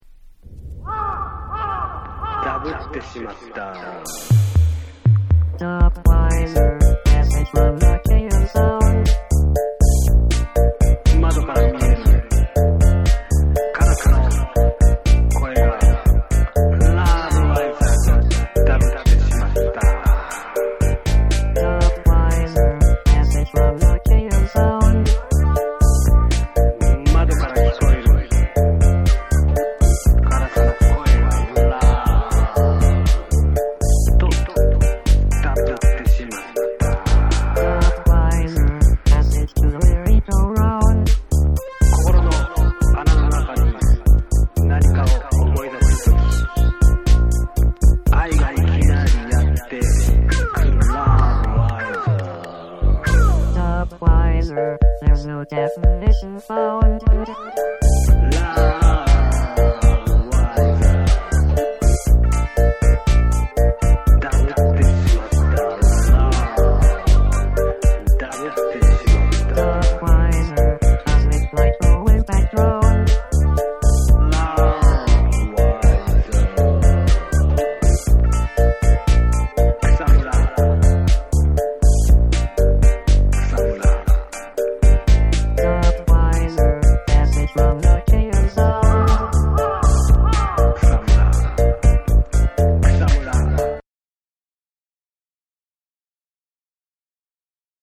哀愁のピアニカが印象的なインスト・レゲエ・ナンバー
デジタル・ダブ・サウンドに日本語ポエトリーとロボットボイス、そしてカラスの鳴き声も飛び出し摩訶不思議な世界観を披露する
JAPANESE / REGGAE & DUB